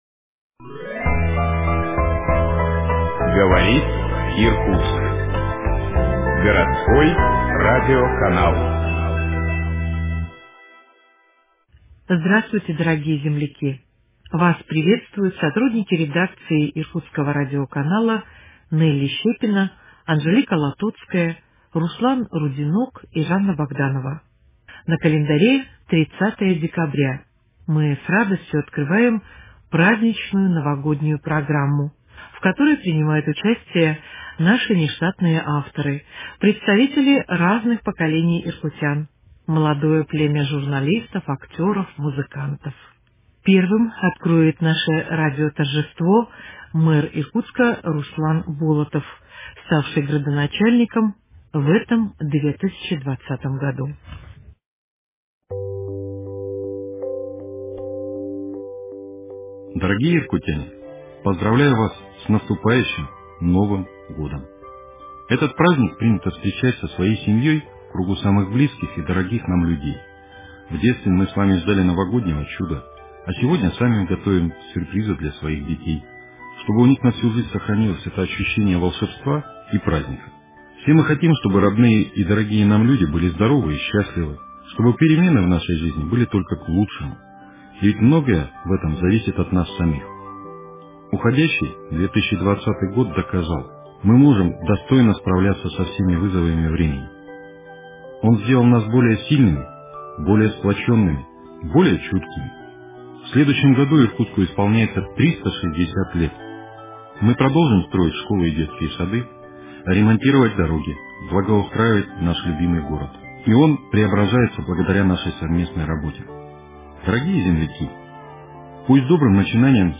Поздравления от детей и молодёжи.